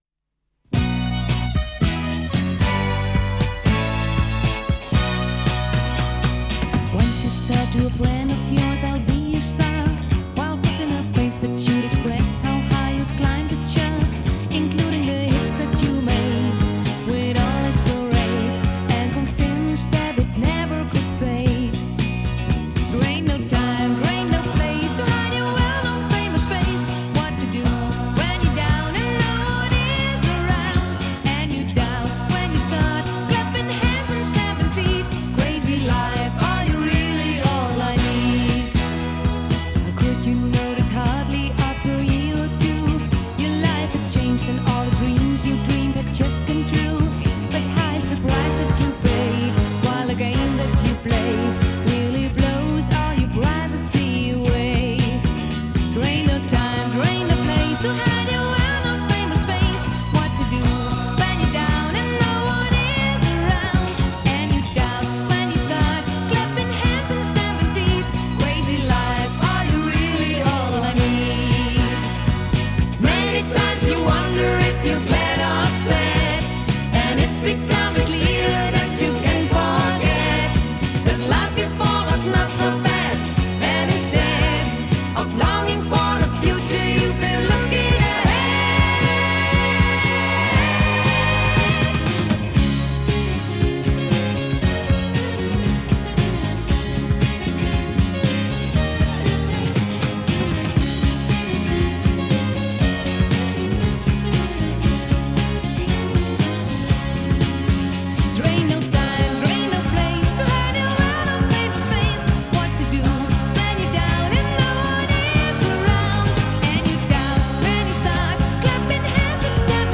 Жанр: Electronic, Rock, Funk / Soul, Pop
Recorded At – Soundpush Studios